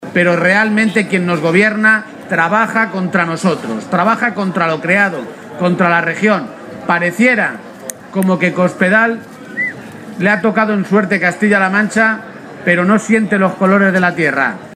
García-Page se pronunciaba de esta manera este mediodía, en una comparecencia ante los medios de comunicación durante su visita a la Feria de Talavera.